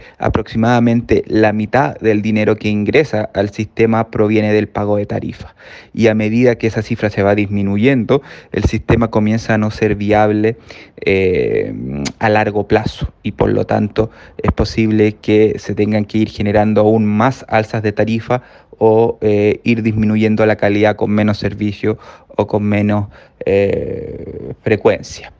El experto en transportes y movilización